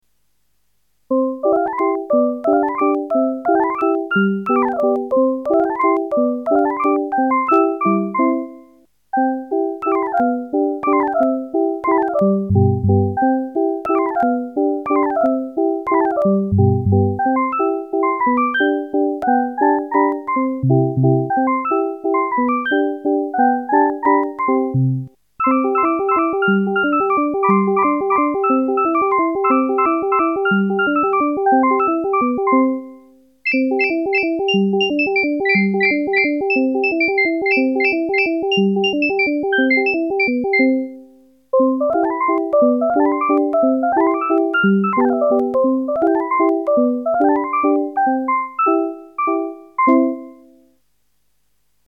Cet instrument est purement virtuel !!!!!! :-)
Vous oubliez l'ancienne version, voici la version 2 du programme, qui sait  lire une partition plus élaborée,  et grace à la carte son SBLive,  on peut  exécuter plusieurs programmes en même temps,  ce qui permet la polyphonie.
Sur cette version, seul l'instrument 1 = piano a été un petit peu travaillé...
Resultats avec ma carte son :